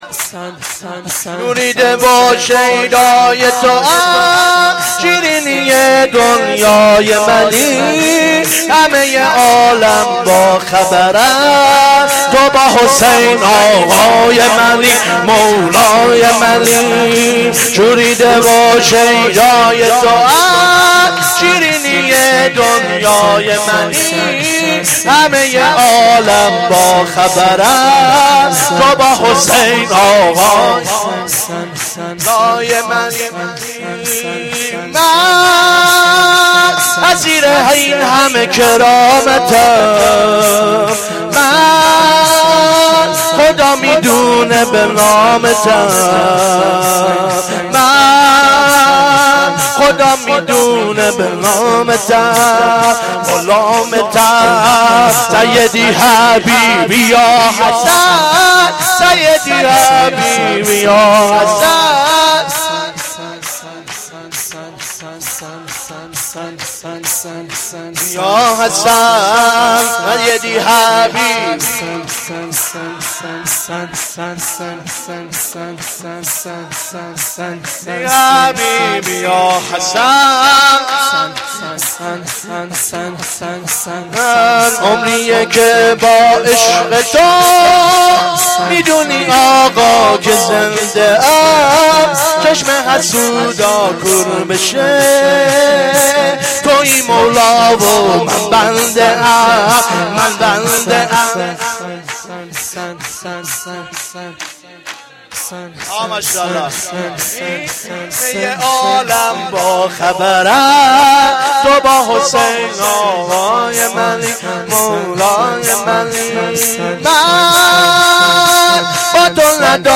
شور
shoor2-Rozatol-Abbas.Milad-Emam-Hasan.mp3